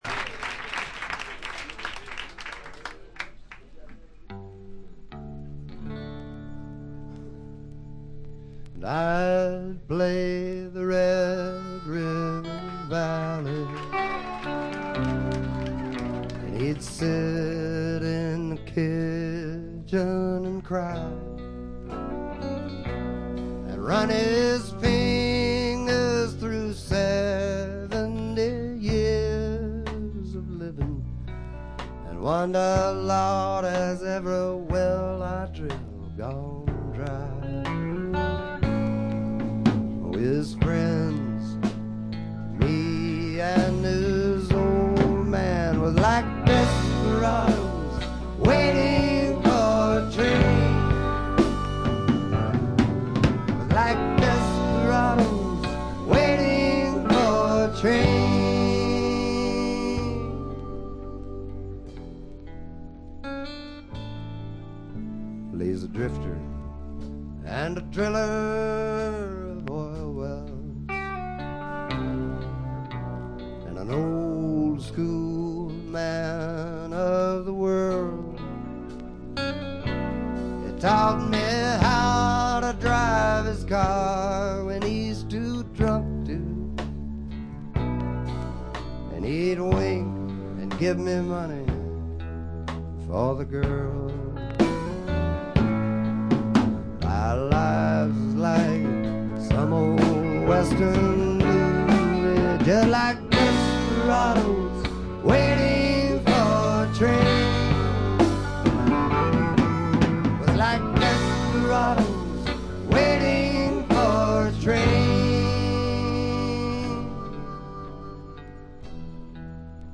A6終盤近くで「ザッ」という周回ノイズが10数回出ます。
試聴曲は現品からの取り込み音源です。